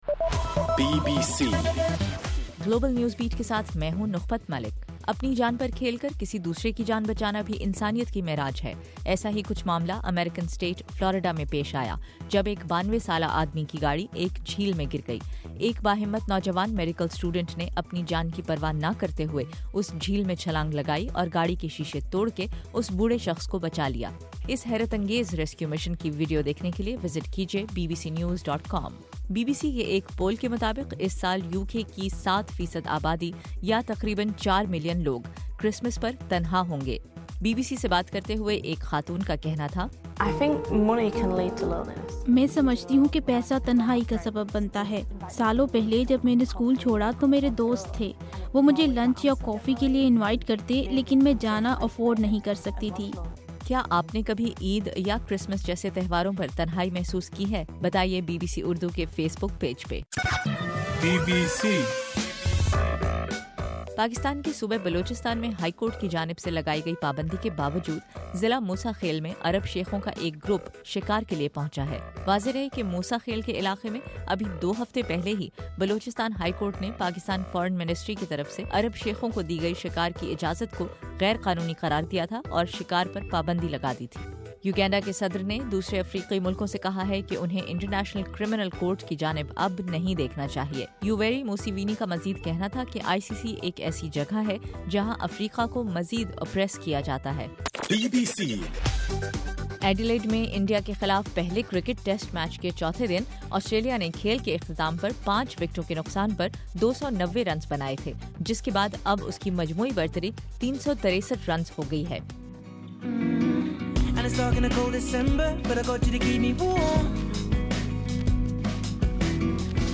دسمبر 13: صبح 1 بجے کا گلوبل نیوز بیٹ بُلیٹن